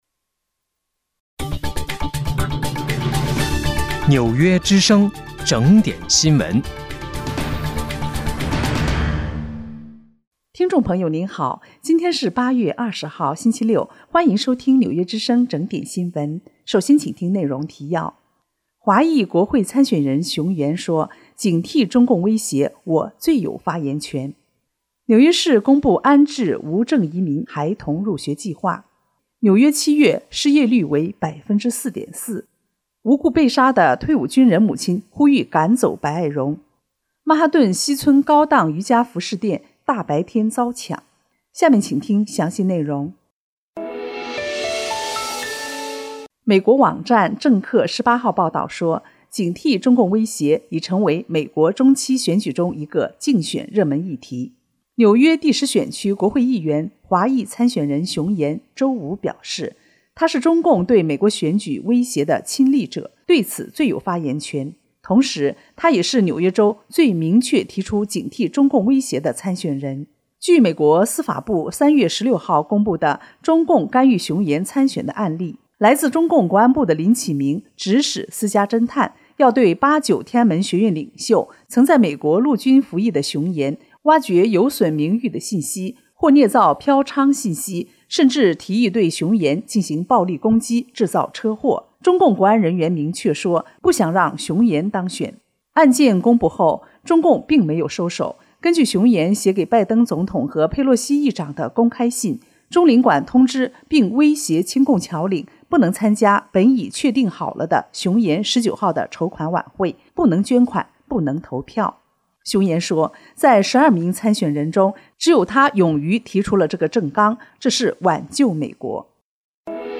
8月20日（星期六）纽约整点新闻